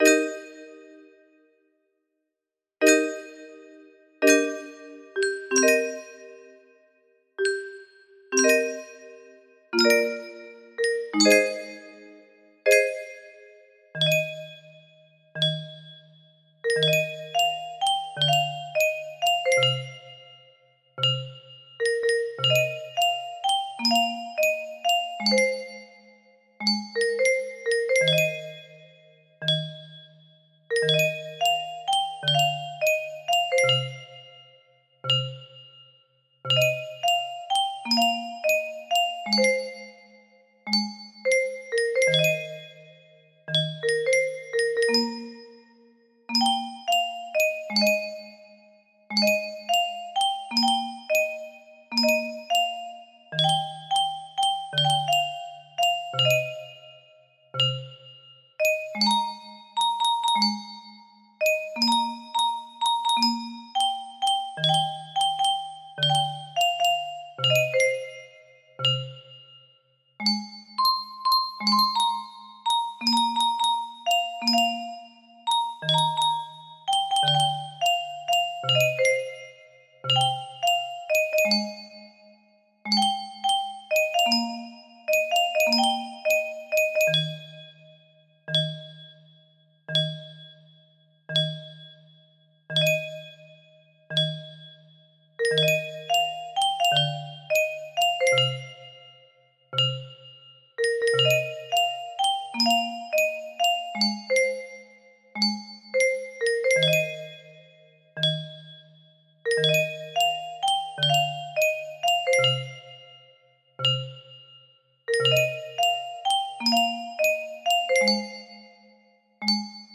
Full range 60
Imported from MIDI File